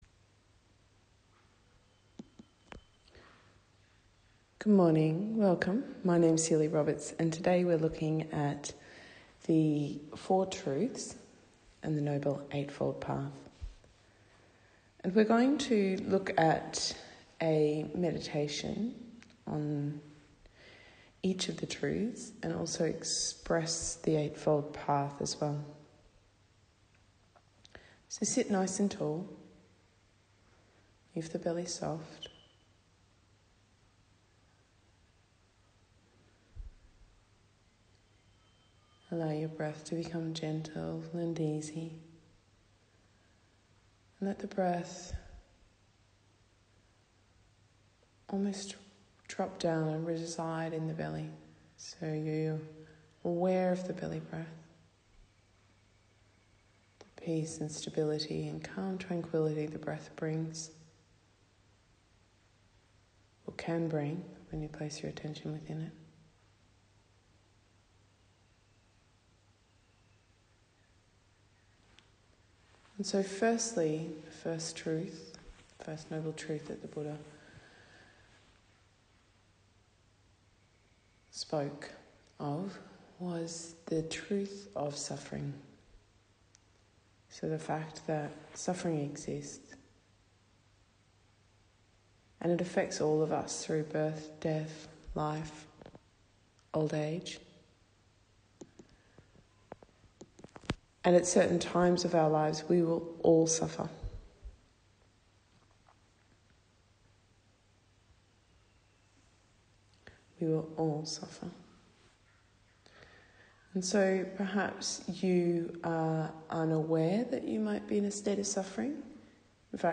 Ajahn Chah Join us in an insightful discussion and contemplative meditation on the four noble truths: the symptoms and causes of suffering, the possibility of healing and the medicinal path to liberation.
Primary practice: Insight Meditation